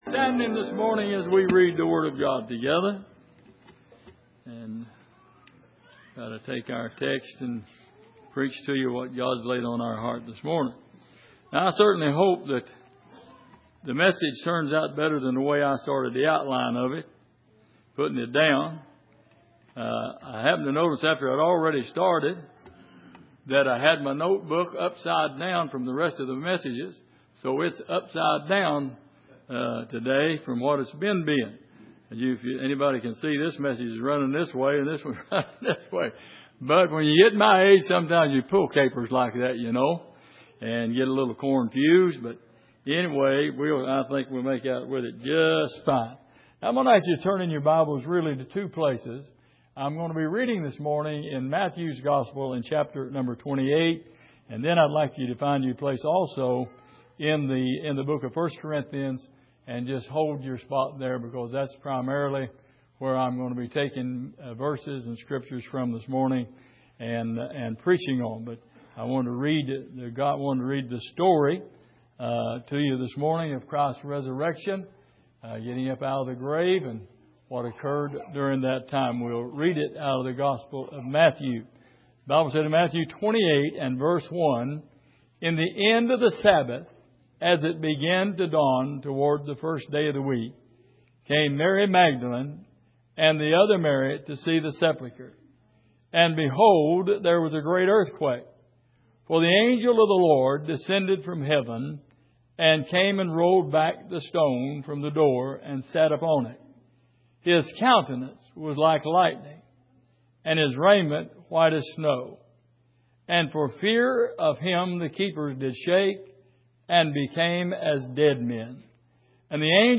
Miscellaneous Passage: Matthew 28:1-7 Service: Sunday Morning The Day The Stone Rolled Away « Is Your Burden Too Heavy To Carry?